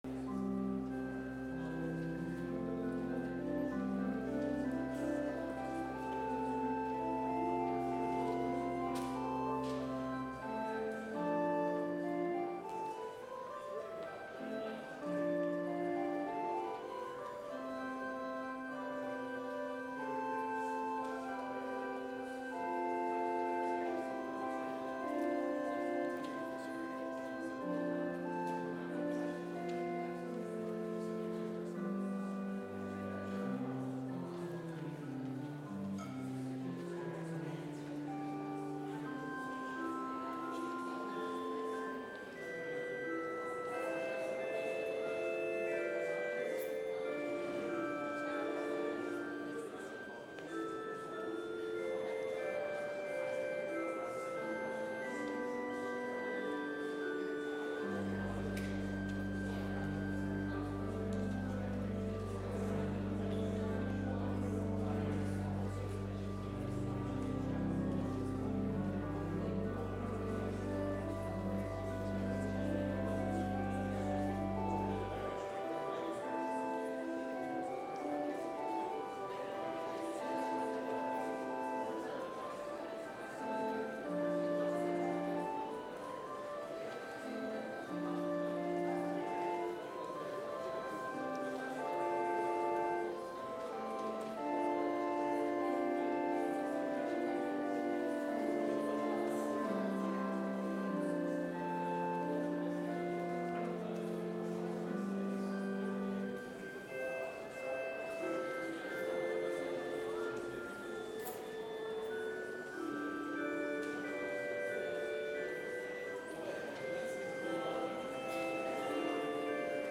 Complete service audio for Chapel - Wednesday, April 3, 2024